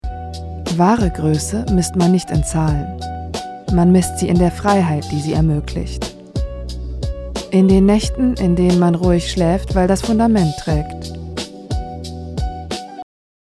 markant
Mittel minus (25-45)
Russian, Eastern European
Comment (Kommentar), Commercial (Werbung)